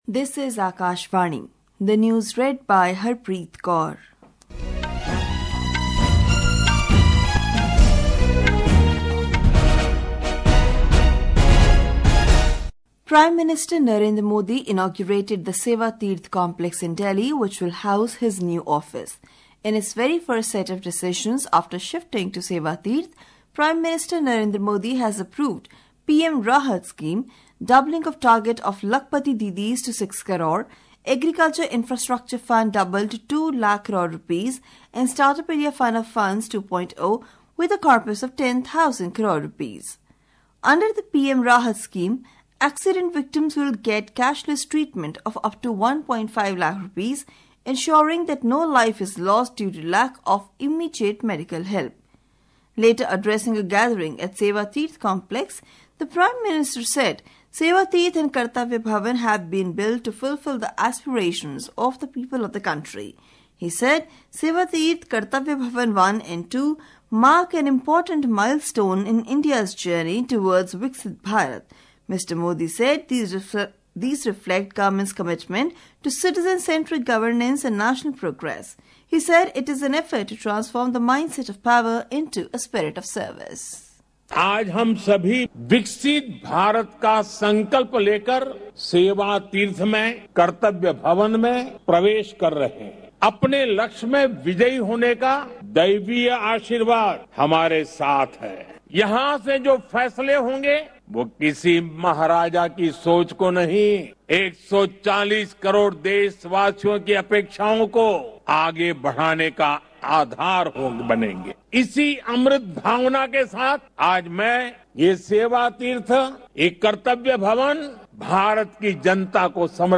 National Bulletins